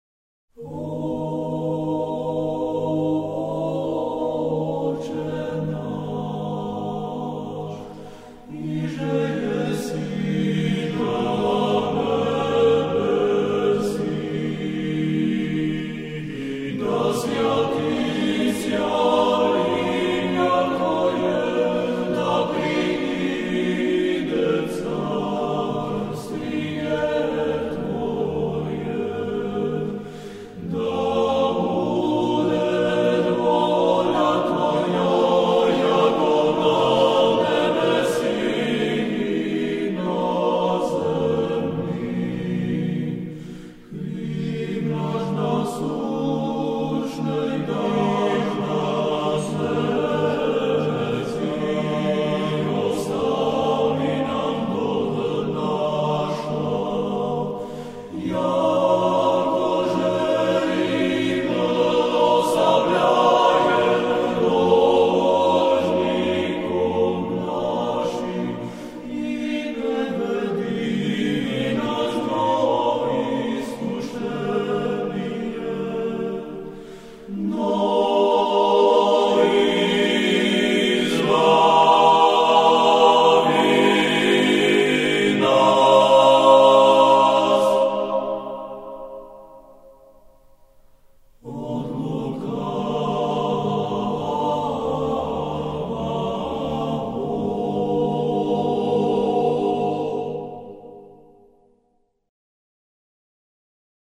Nahr�vka je live z�znamom koncertu z 24. septembra 2005 v Slanom